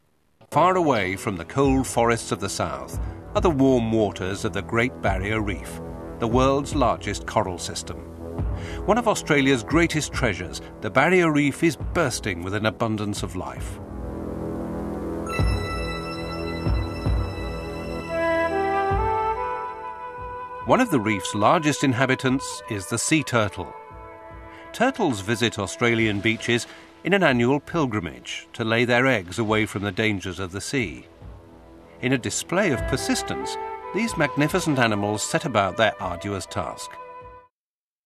Documentary narrator/voice over